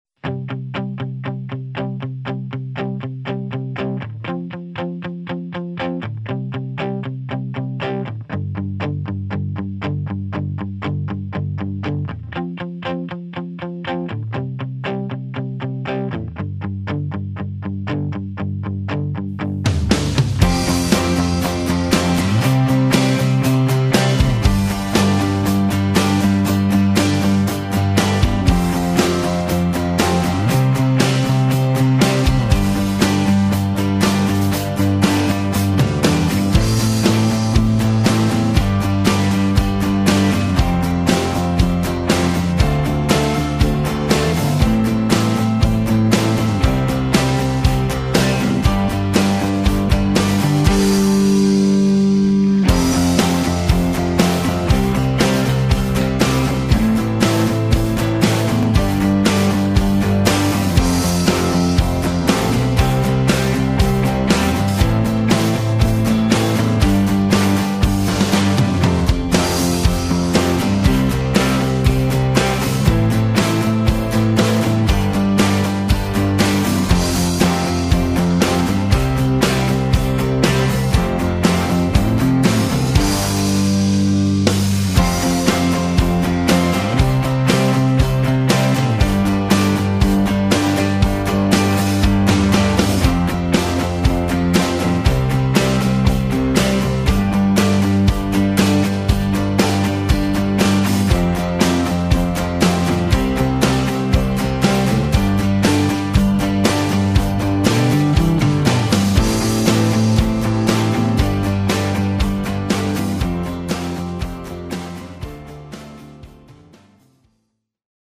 Backing Track – High Key without Backing Vocals